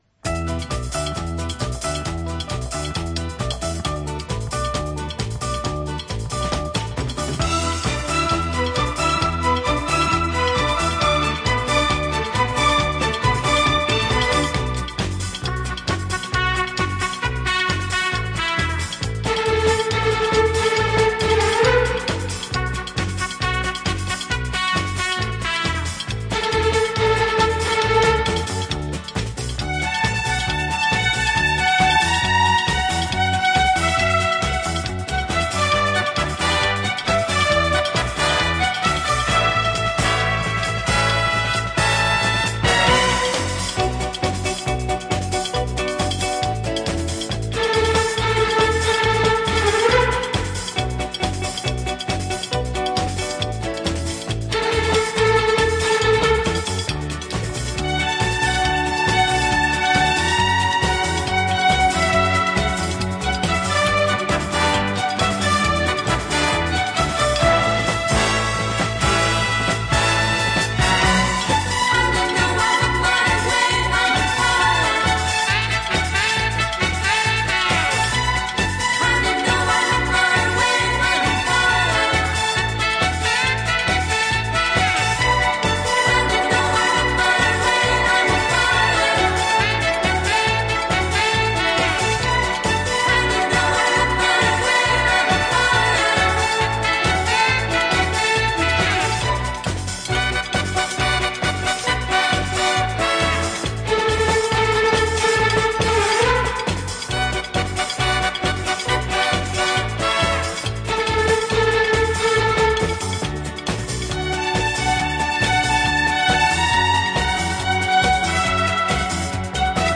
Жанр: Easy Listening
Носитель: LP